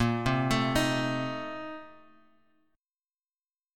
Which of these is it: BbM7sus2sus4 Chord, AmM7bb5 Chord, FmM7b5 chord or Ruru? BbM7sus2sus4 Chord